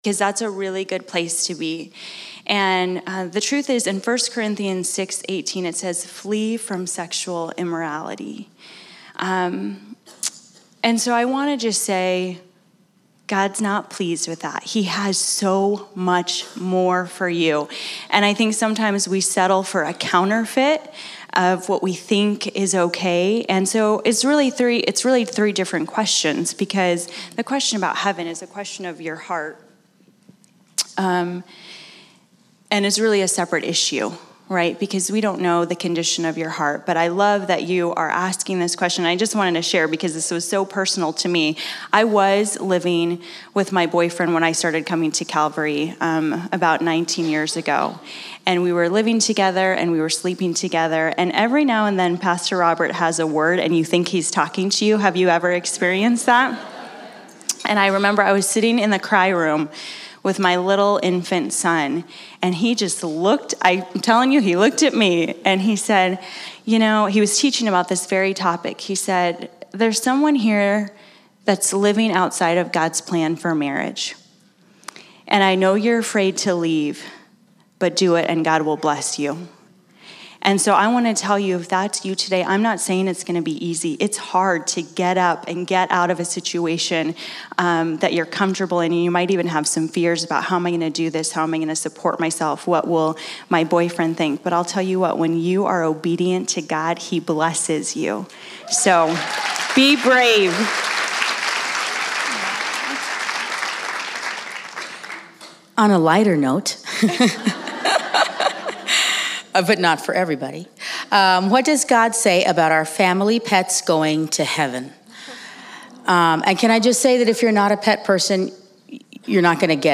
2018 Women's Retreat: Q&A - Calvary Tucson Church
2018 Women's Retreat: Q&A Sep 7, 2018 Listen to the Q&A at the 2018 Women's Retreat: Pray, Come Boldly Before His Throne.